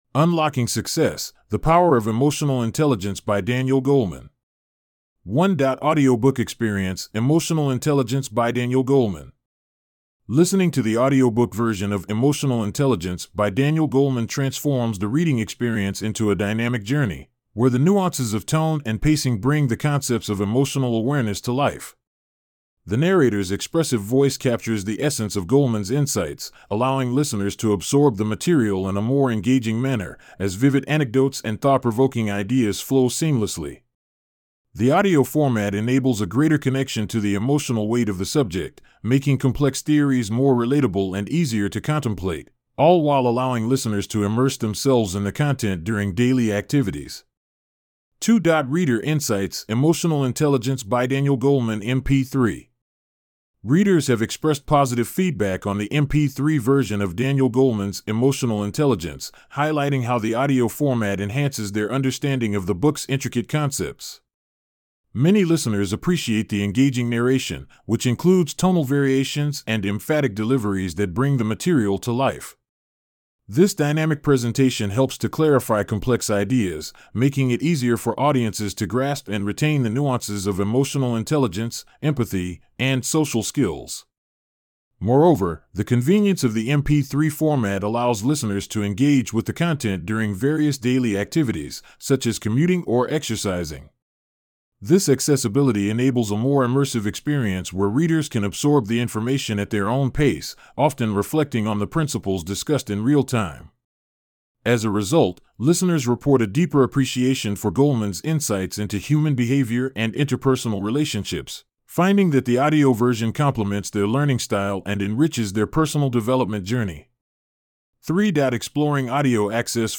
Audiobook Experience:Emotional Intelligence byDaniel Goleman